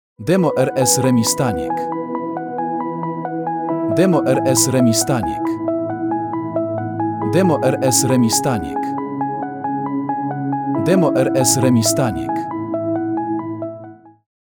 💧 Subtelne brzmienie i nowoczesna produkcja
🎶 Dźwięk, który tworzy atmosferę i zostaje w głowie